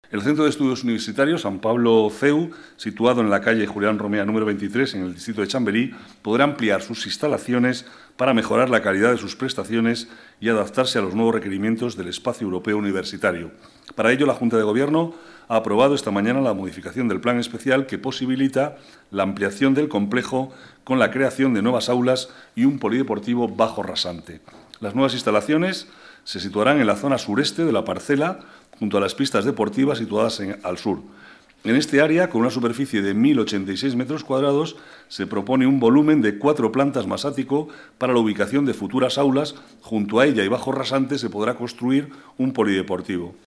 Nueva ventana:Declaraciones vicealcalde, Manuel Cobo: ampliación instalaciones del CEU